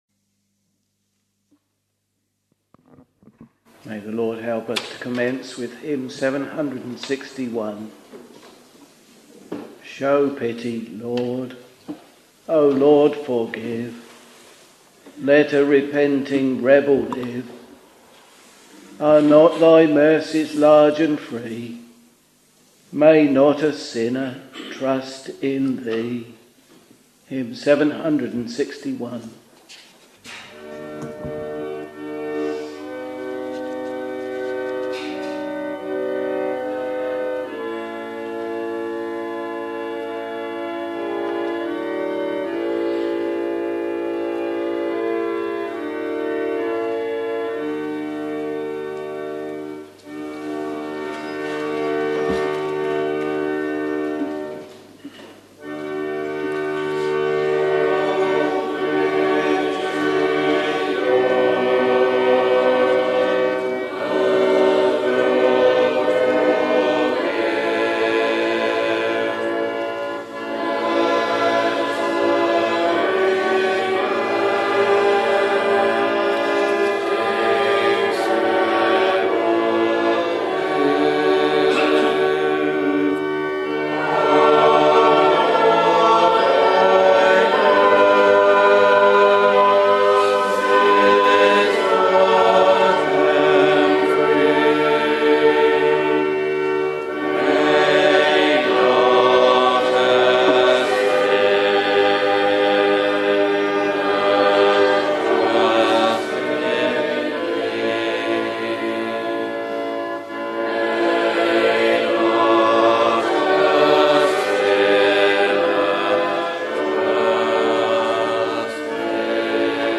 Morning Service Preacher